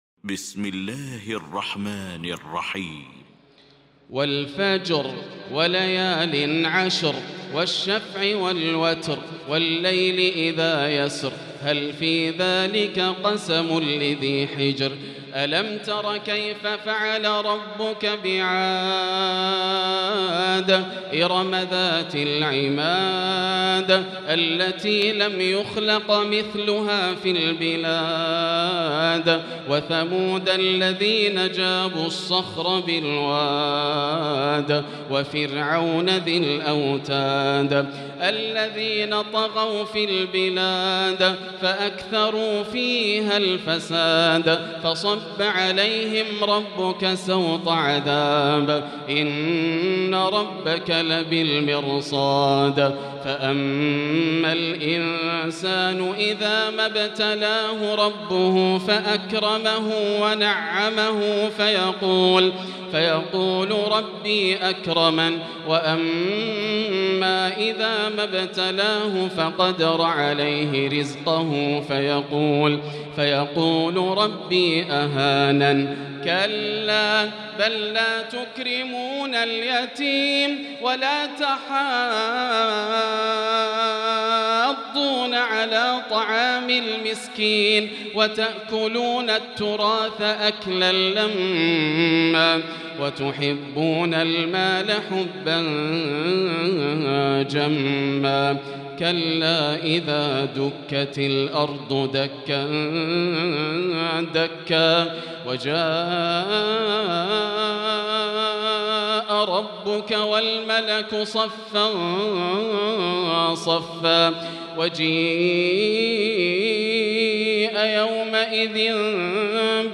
المكان: المسجد الحرام الشيخ: فضيلة الشيخ ياسر الدوسري فضيلة الشيخ ياسر الدوسري الفجر